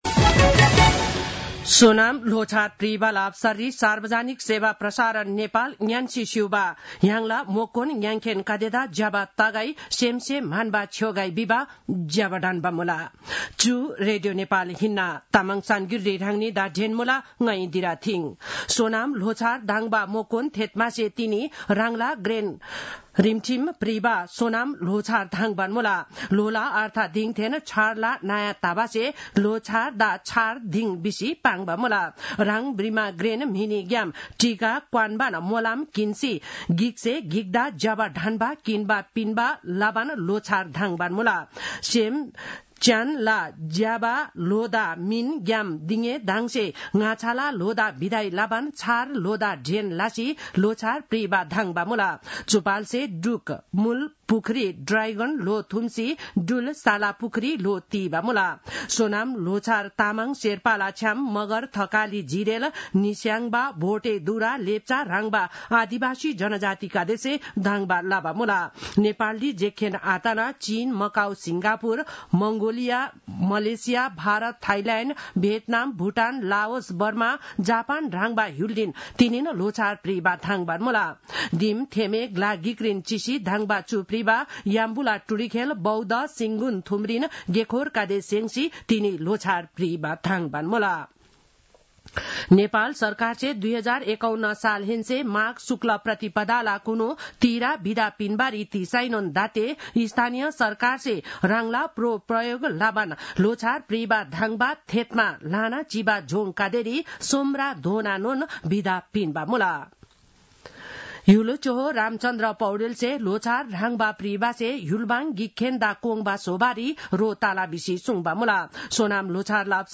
An online outlet of Nepal's national radio broadcaster
तामाङ भाषाको समाचार : १८ माघ , २०८१
Tamang-news-10-17.mp3